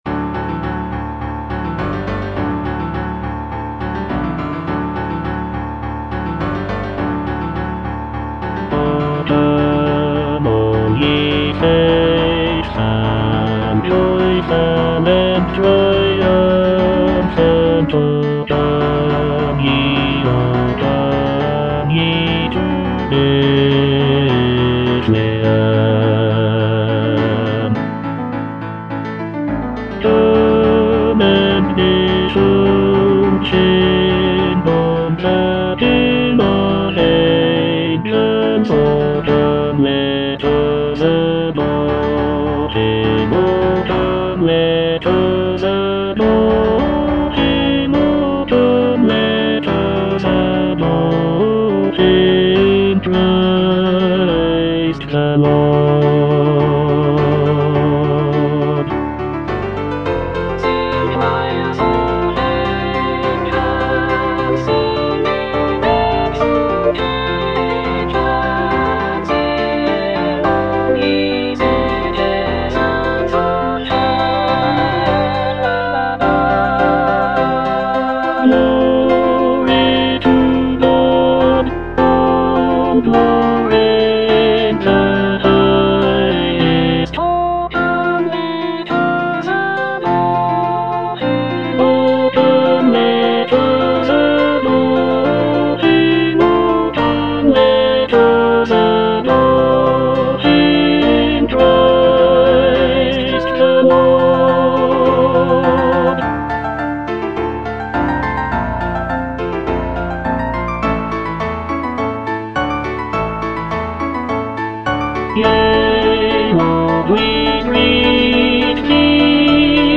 Tenor II (Emphasised voice and other voices)
Christmas carol
incorporating lush harmonies and intricate vocal lines.